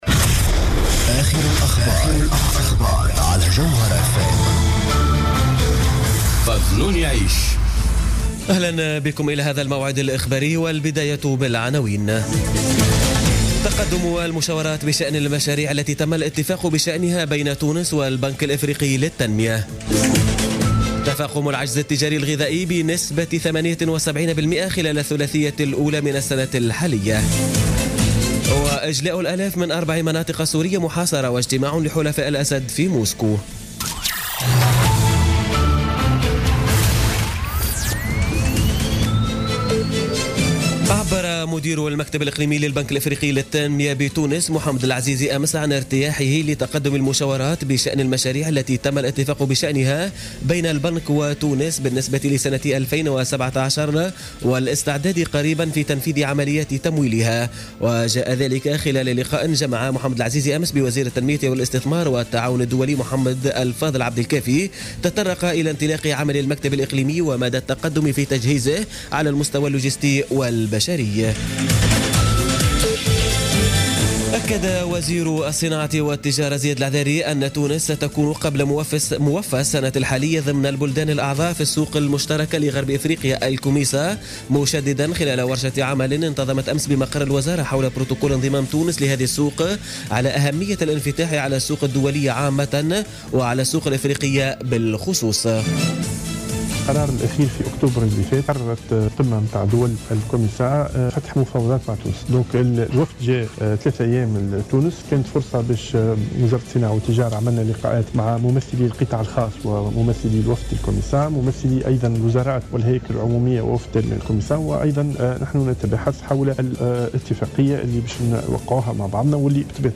نشرة أخبار منتصف الليل ليوم السبت 15 أفريل 2017